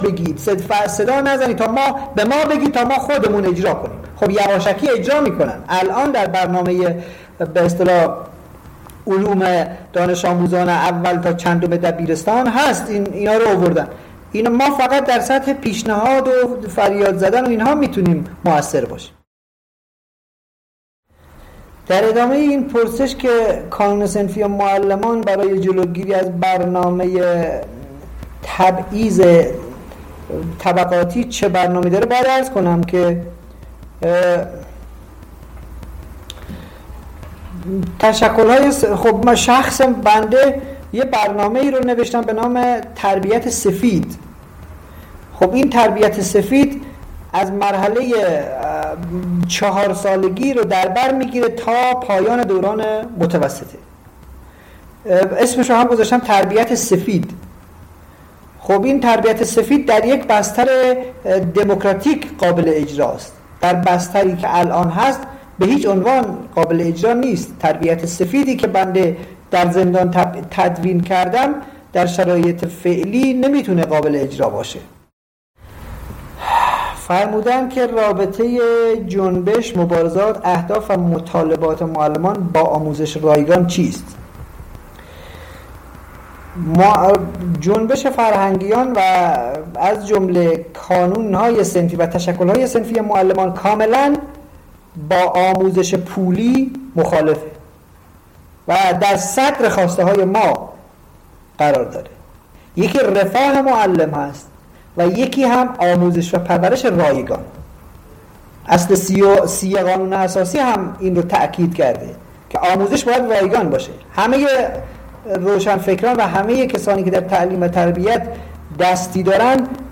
دربرنامه پرسش و پاسخ تلگرامی به مناسبت روز معلم
این جلسات معمولا ” هر جمعه ساعت ۹ شب به وقت ایران به طور موازی در بیش از ده گروه و کانال تلگرامی از جمله گروه ” به یاد رفیق رئیس دانا و برای اتحاد عدالتخواهان ” ، حمایت از زندانیان سیاسی و عقیدتی و کانال تریبون سرزمین من (اجتماعی،سیاسی،فرهنگی) برگزار میشود.